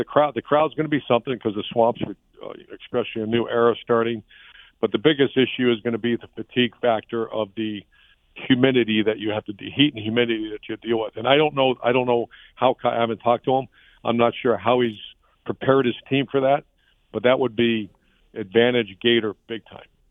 In an interview on Sportscene